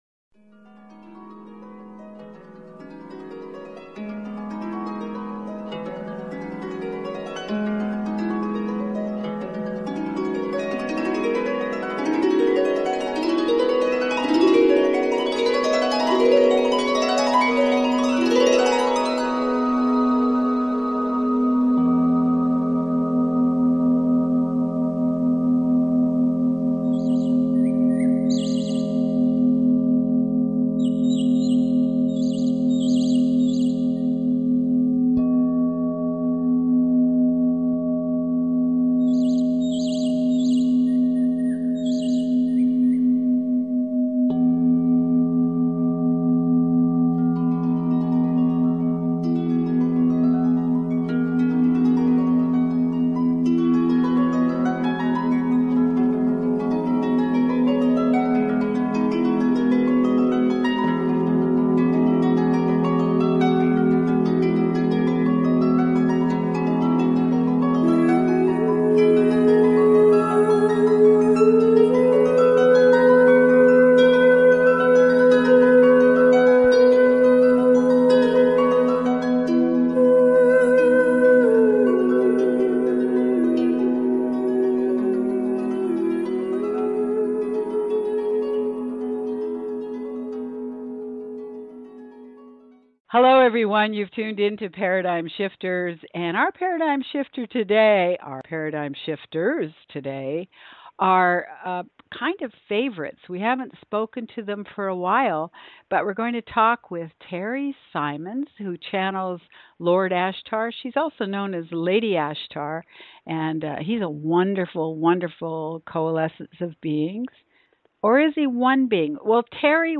Paradigm Shifters interview with Ashtar